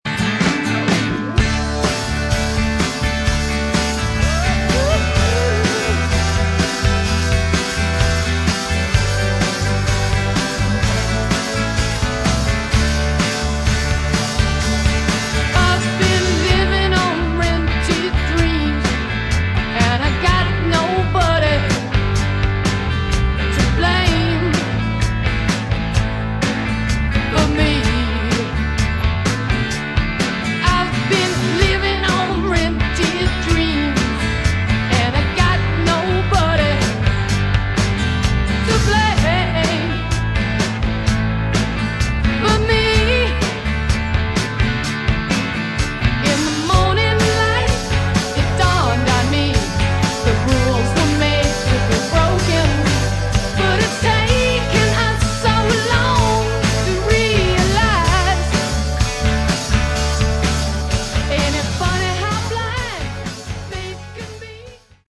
Category: Hard Rock
vocals
guitar
drums
keyboards, bass